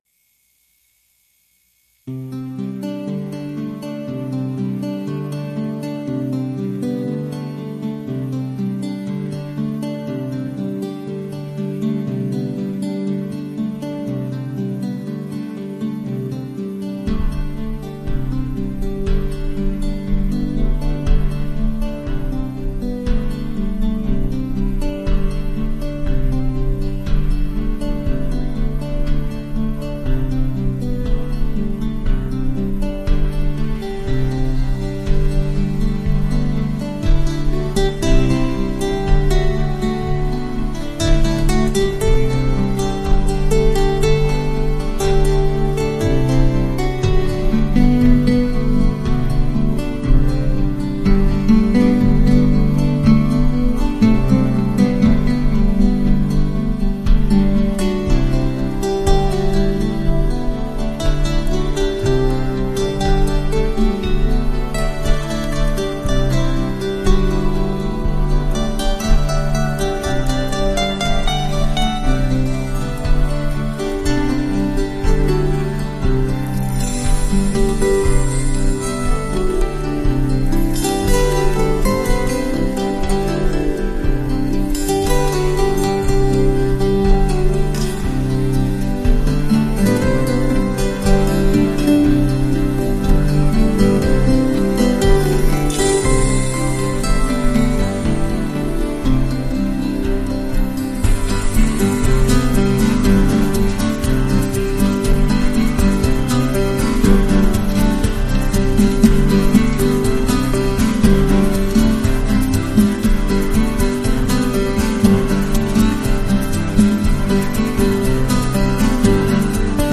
・メインセクションは、アコースティックギターとピアノが中心となり、リズミカルなパーカッションが加わります。